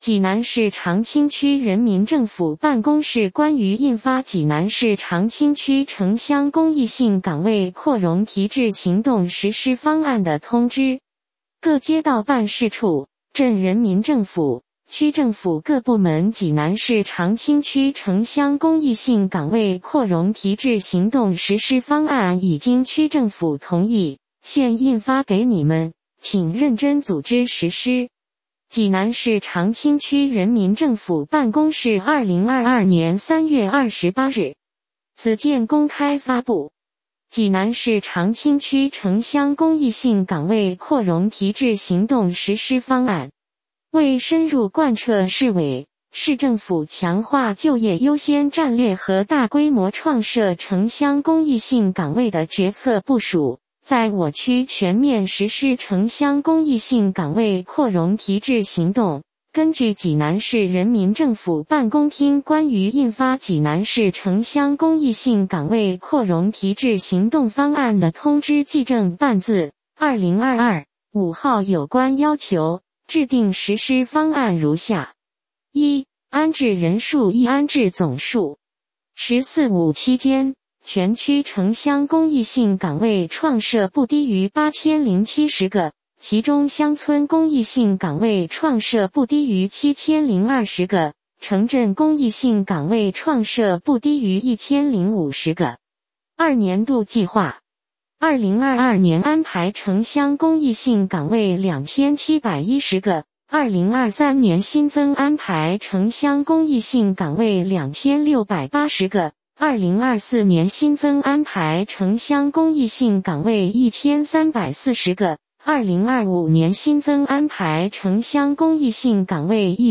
济南市长清区人民政府 - 有声朗读 - 有声朗读：济南市长清区人民政府办公室关于印发《济南市长清区城乡公益性岗位扩容提质行动实施方案》的通知